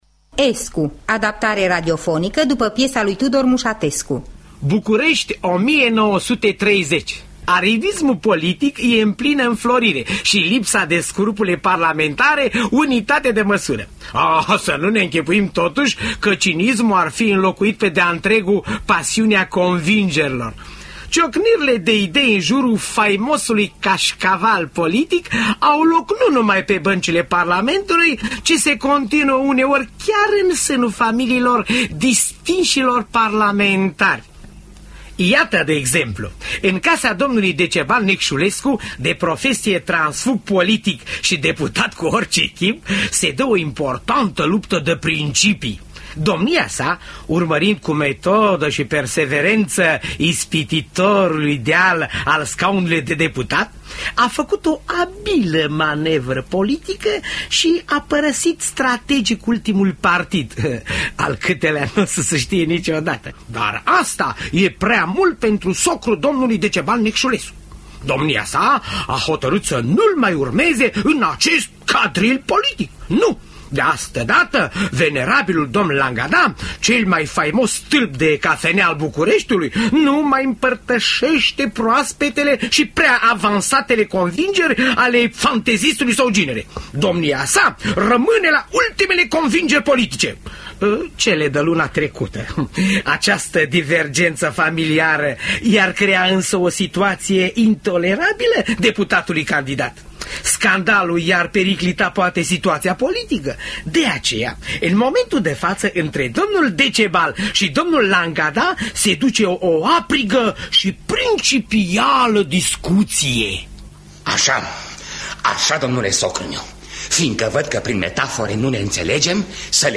Escu de Tudor Muşatescu – Teatru Radiofonic Online